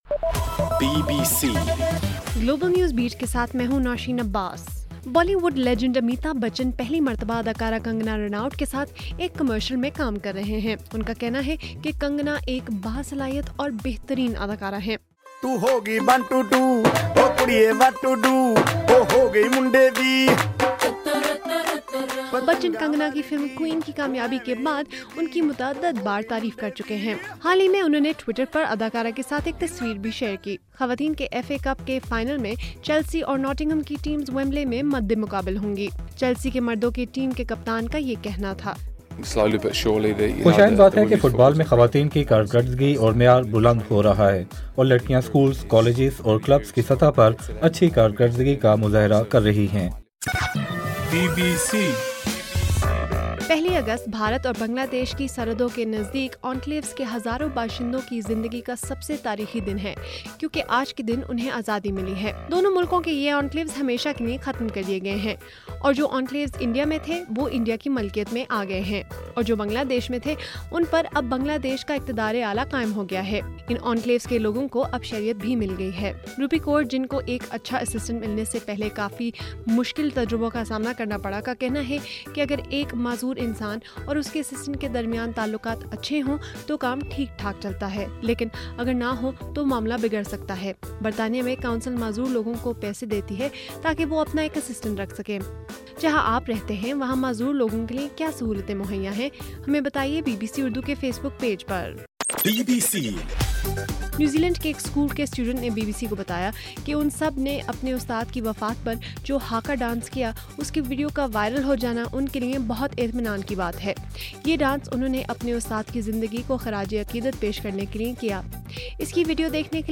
اگست 1: رات 12 بجے کا گلوبل نیوز بیٹ بُلیٹن